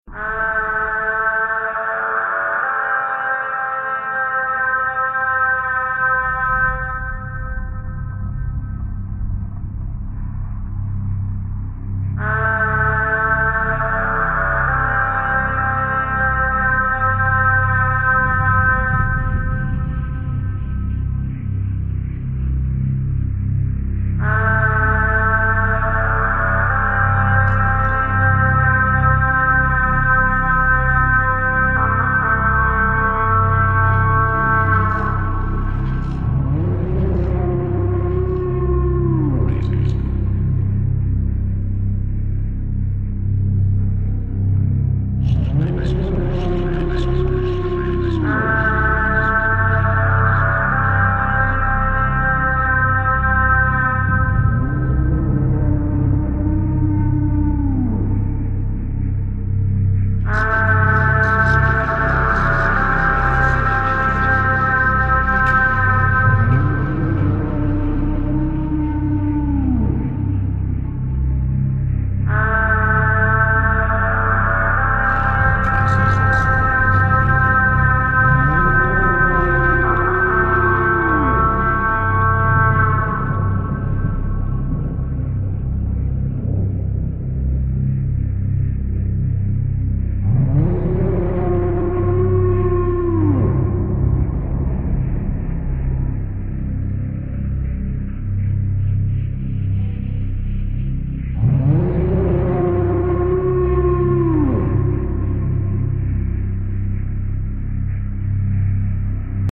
the Welsh Godfather of Dark Ambient, recorded live in 2006